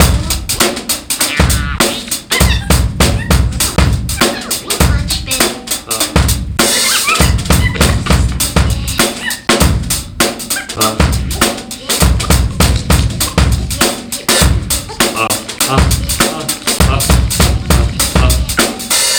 Breakbeat 2
Drum Crazy 100bpm